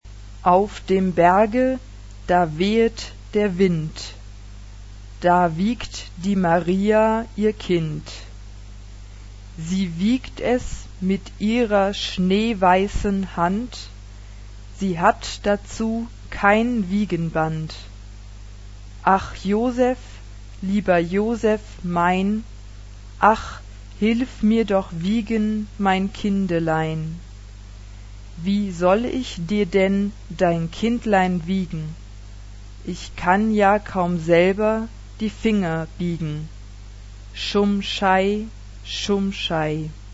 Pronunciation.